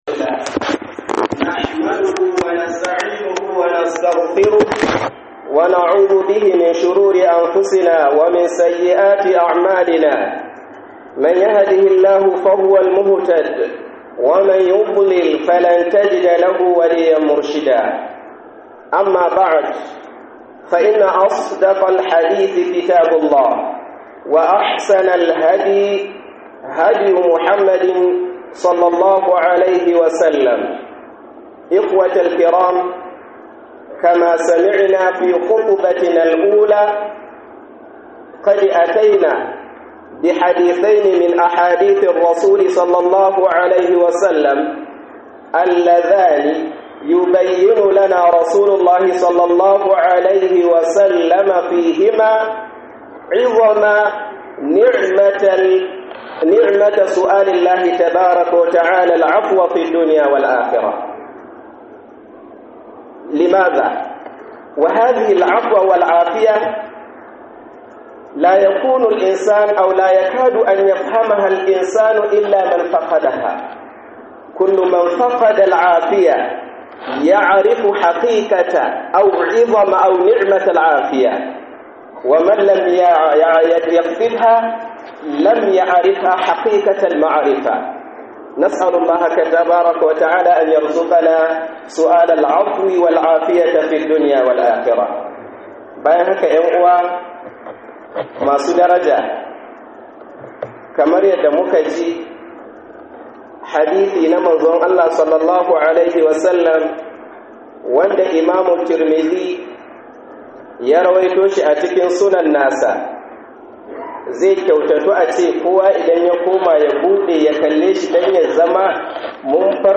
Khudubar Jibwis NEPA Ningi - Neman yafiya - KHUDUBAR JUMA'AH